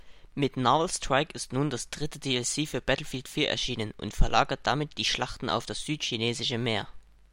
Noise-Cancelling funktioniert bestens
Das Sennheiser G4me One in normaler Umgebung – ein Raum ohne große Hintergrundgeräusche